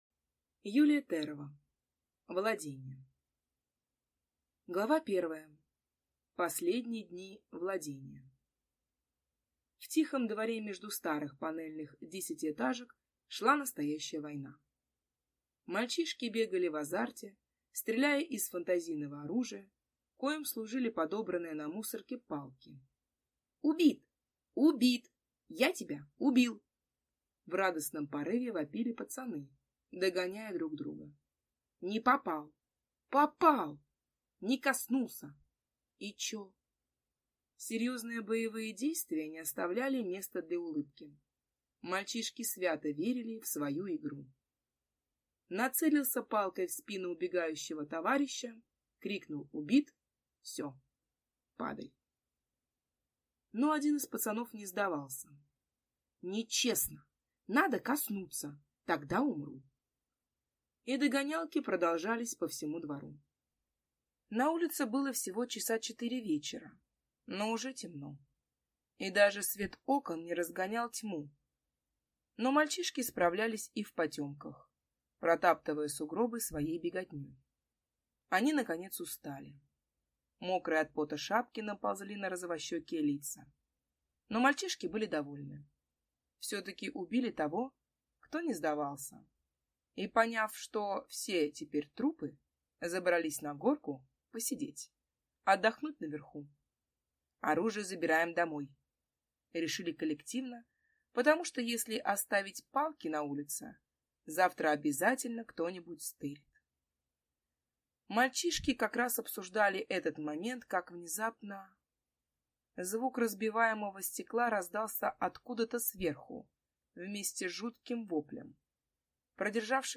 Аудиокнига Владение | Библиотека аудиокниг
Прослушать и бесплатно скачать фрагмент аудиокниги